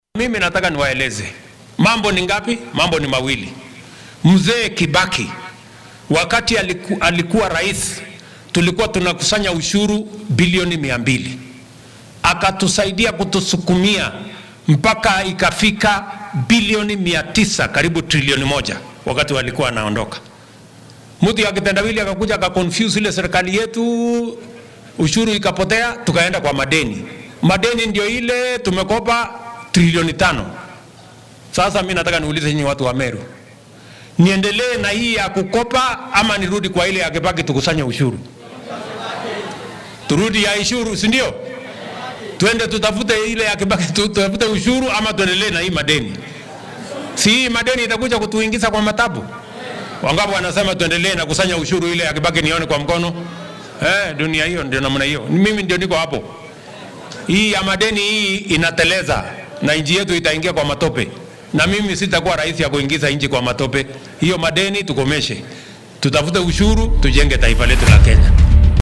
Madaxweynaha dalka William Ruto ayaa xilli uu ku sugnaa ismaamulka Meru waxaa uu sheegay in isbeheysiga talada haya ee Kenya Kwanza uu sii wadi doono inuu canshuurta uruuriyo si sare loogu qaado dakhliga wadanka soo galo.